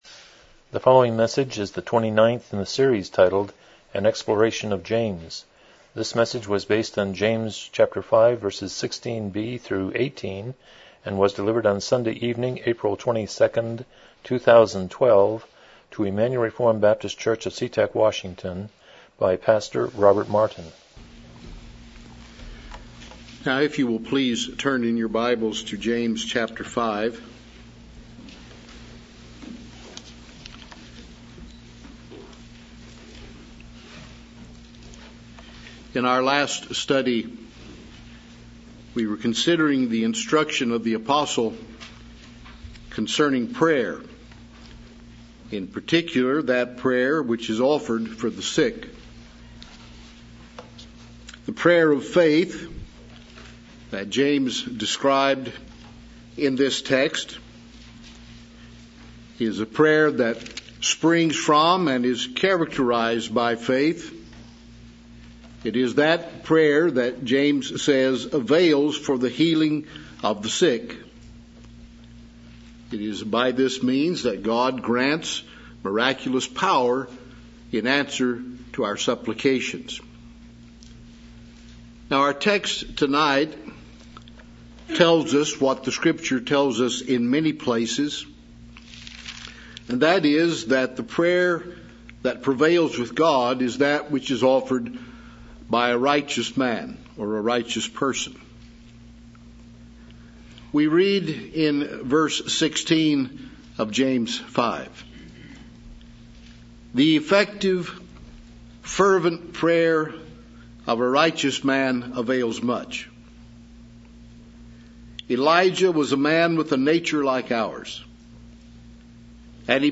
James 5:16-18 Service Type: Evening Worship « 167 Romans 16:20b